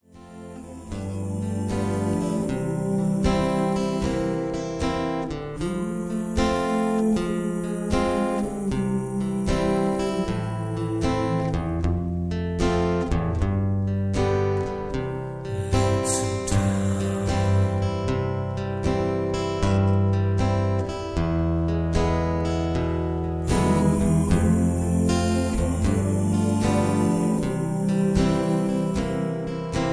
Just Plain & Simply "GREAT MUSIC" (No Lyrics).